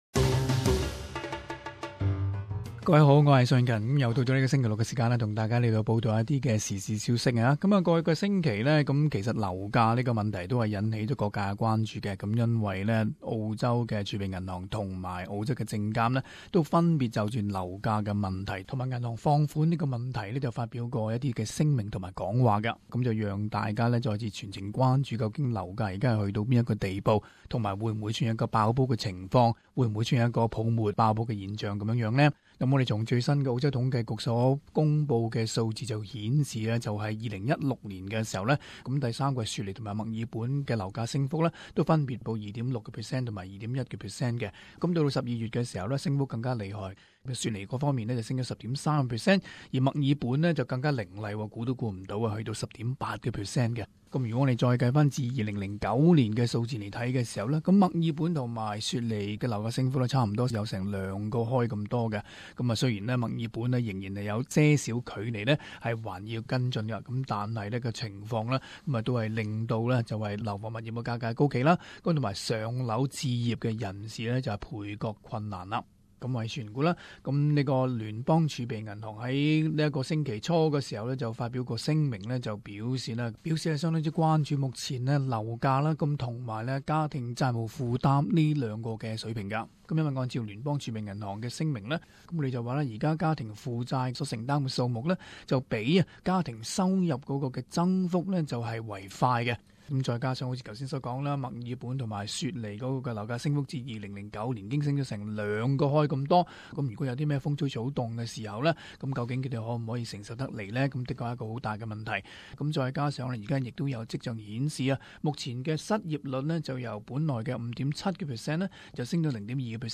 【時事報導】儲備銀行關注樓價高企現象